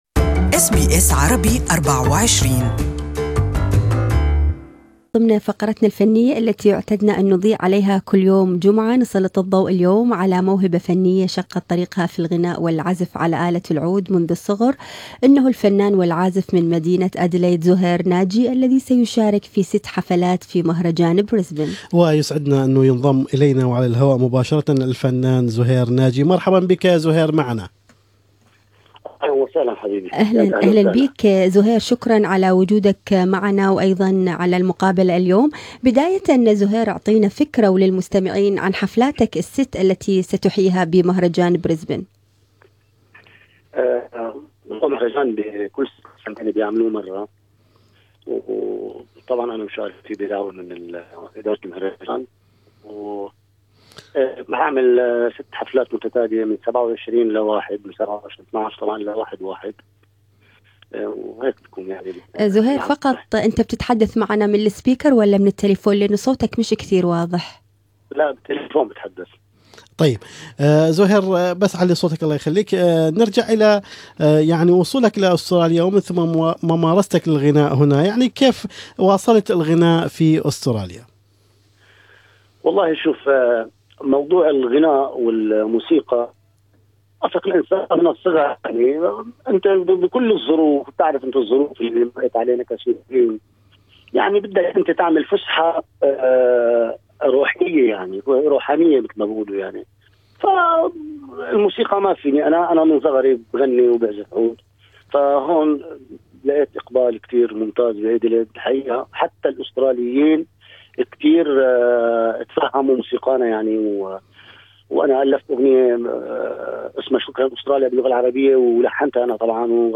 This interview is only available in Arabic